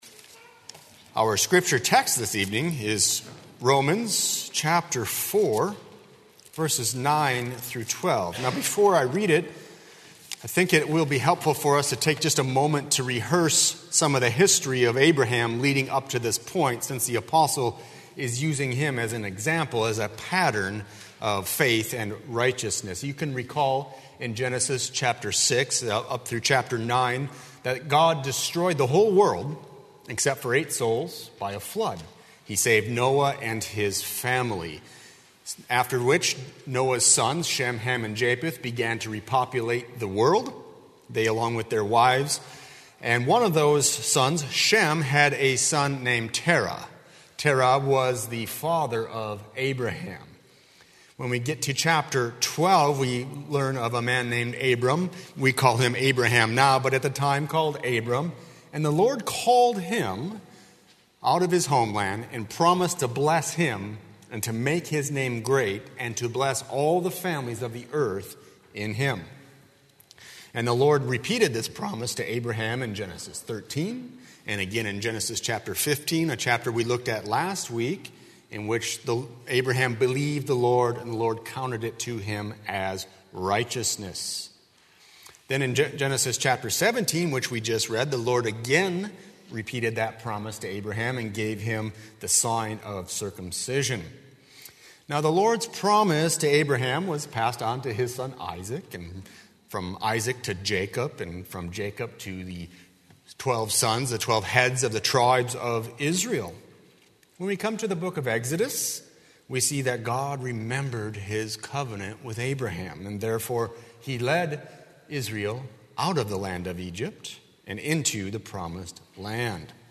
00:00 Download Copy link Sermon Text Romans 4:9–12